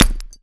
grenade_hit_concrete_hvy_01.WAV